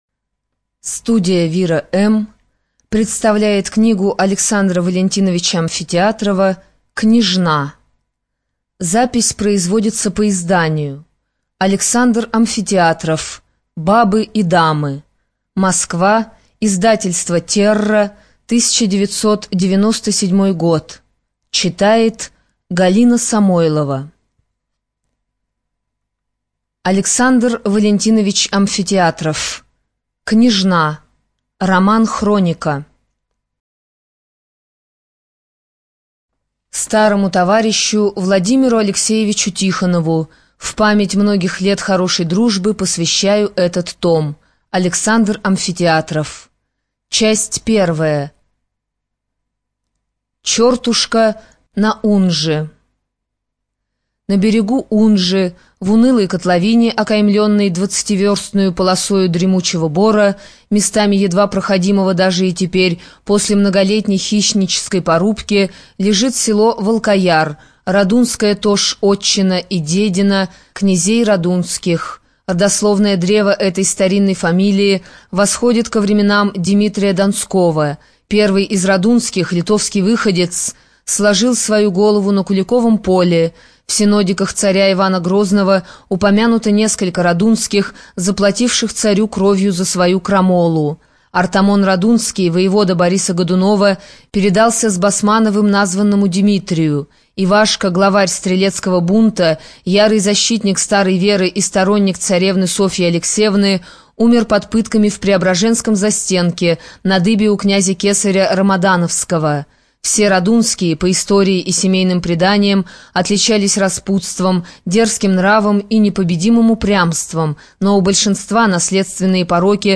ЖанрКлассическая проза
Студия звукозаписиВира-М